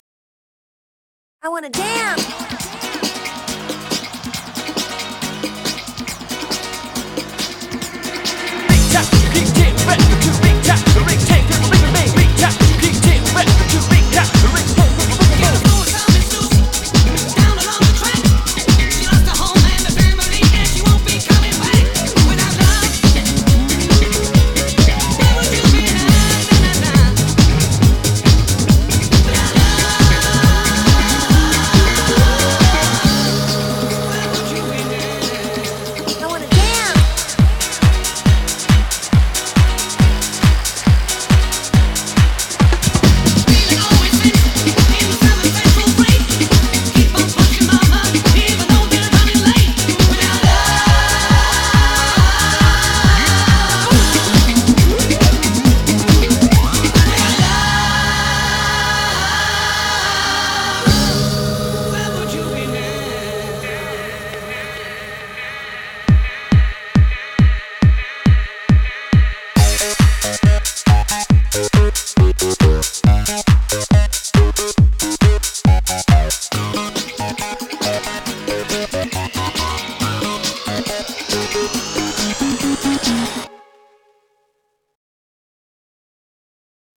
BPM34-138
MP3 QualityMusic Cut